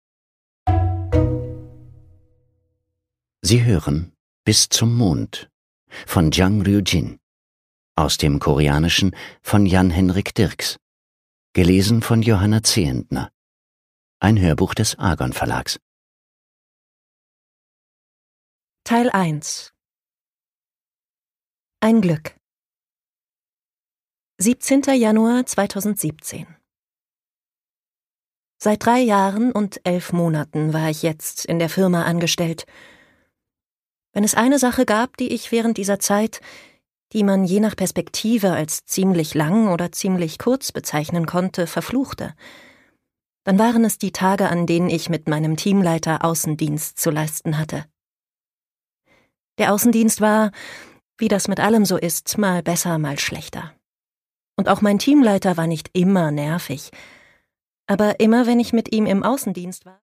Jang Ryujin: Bis zum Mond (Ungekürzte Lesung)
Produkttyp: Hörbuch-Download